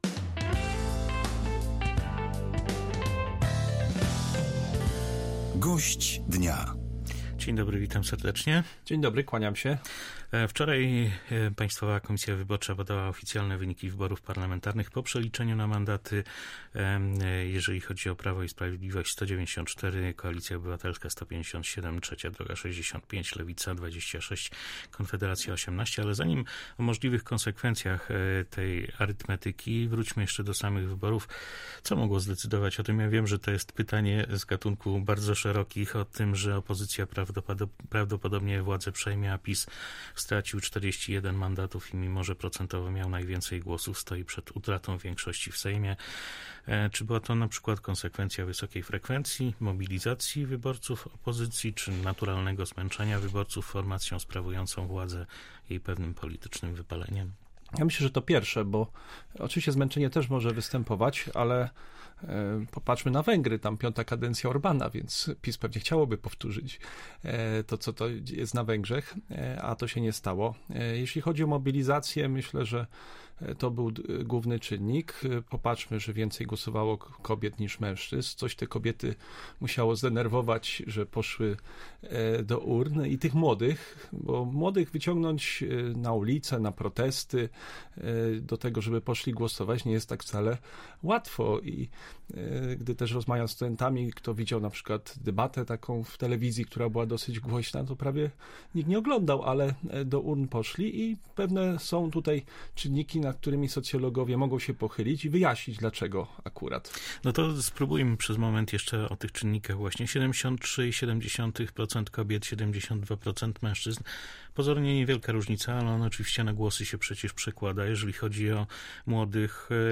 Mimo osiągnięcia najlepszego w wyborach Prawo i Sprawiedliwość ma bardzo nikłe szanse na utworzenie rządu - uważa dzisiejszy gość Radia